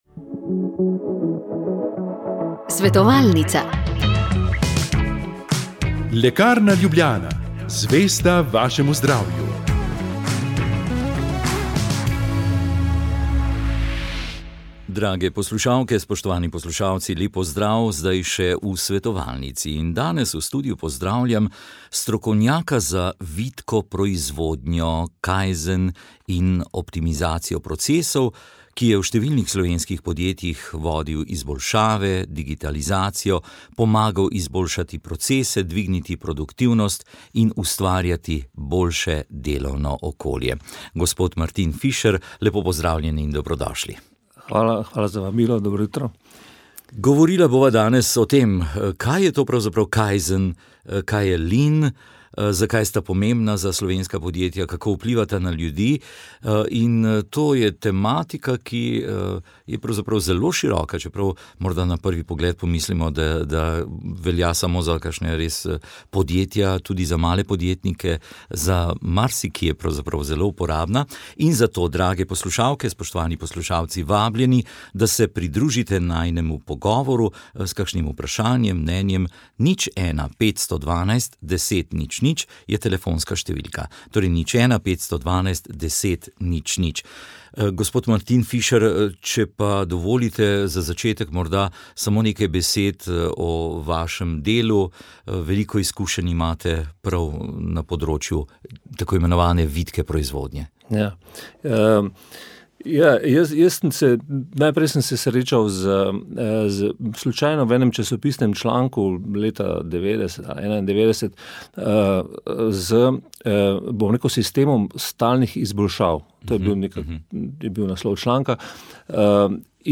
V tokratni Svetovalnici je bil gost v našem studiu predsednik vlade in predsednik stranke SDS Janez Janša. Tik pred volilno nedeljo je odgovarjal zlasti na vprašanja poslušalk in poslušalcev. Ta so se nanašala na vojno v Ukrajini in podporo Evropske unije tej države, na demografske izzive v prihodnosti, na delo organov pregona, na pokojninski sistem, nismo pa mogli niti mimo soočenja na RTV Slovenija.